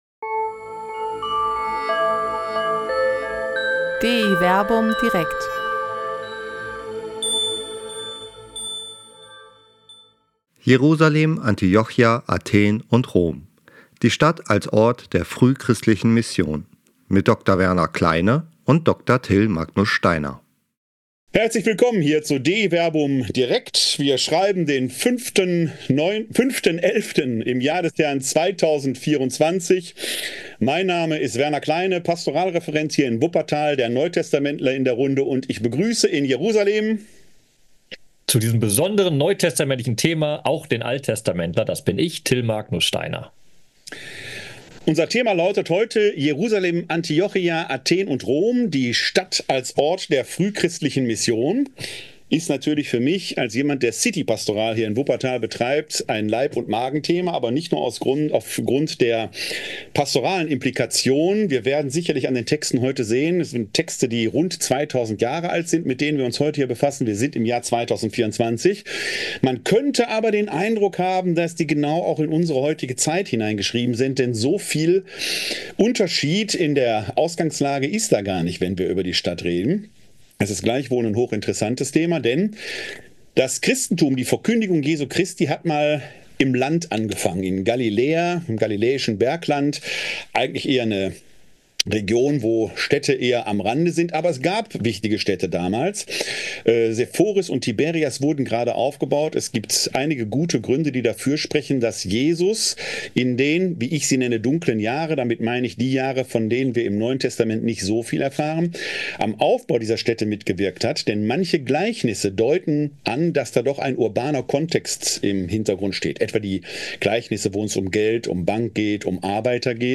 per Zoom über die Stadt als Ort der frühchristlichen Mission.